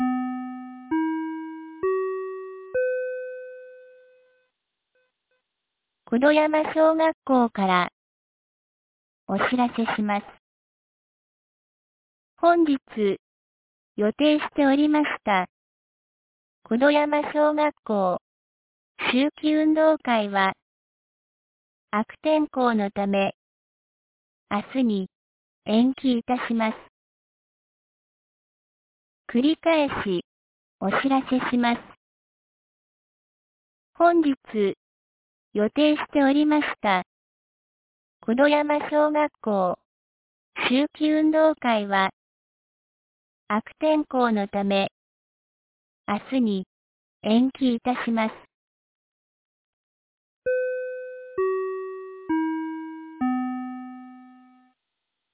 2025年10月04日 08時01分に、九度山町より全地区へ放送がありました。